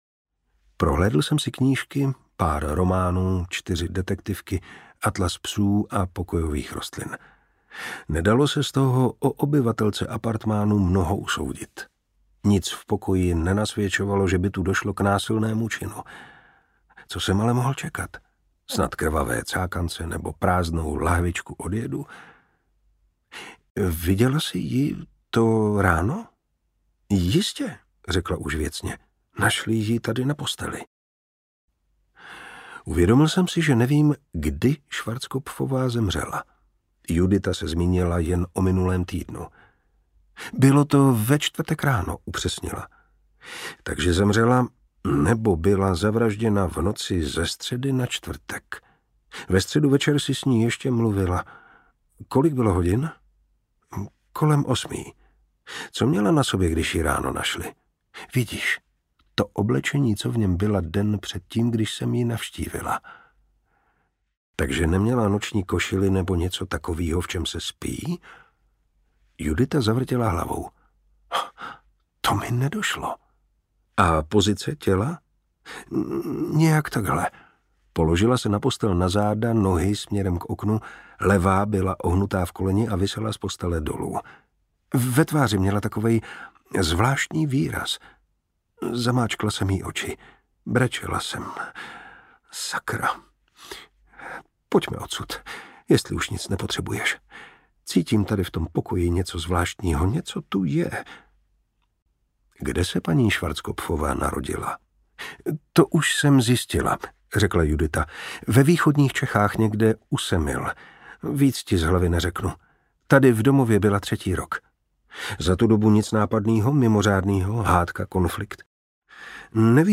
Na šábes se nevraždí audiokniha
Ukázka z knihy
Čte Martin Preiss.
Vyrobilo studio Soundguru.